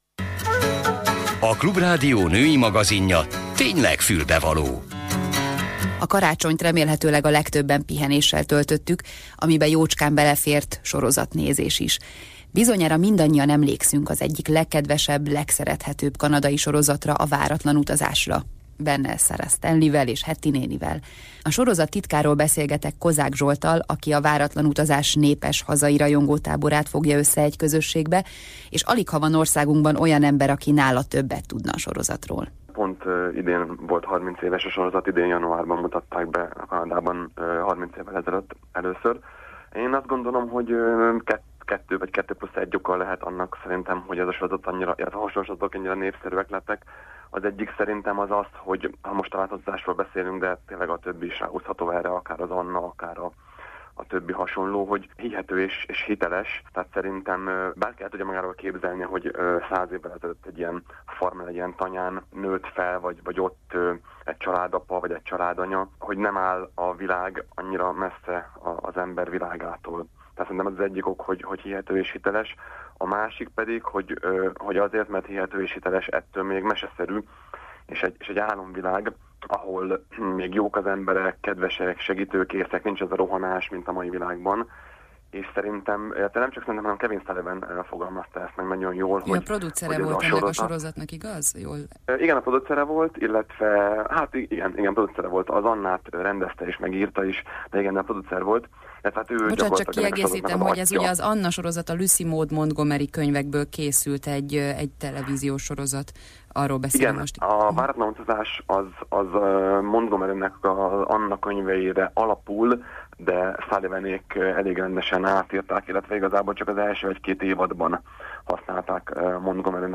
Az interjú december 29-én 13 óra után ment le a csatorna Fülbevaló című adásában.